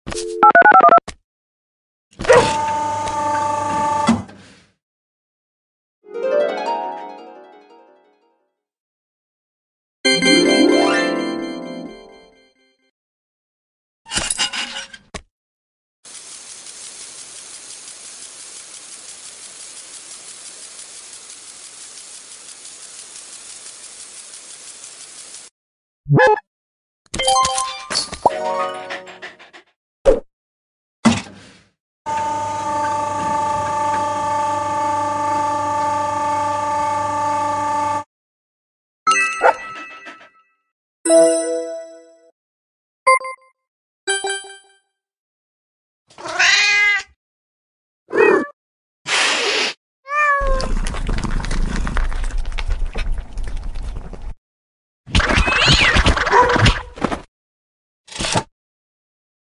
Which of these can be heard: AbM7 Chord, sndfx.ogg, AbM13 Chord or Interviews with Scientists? sndfx.ogg